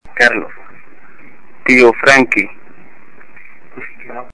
The following EVP examples were collected during the group tour of the Gable Lombard penthouse in the Hollywood Roosevelt Hotel. All are Class C to B quality voices.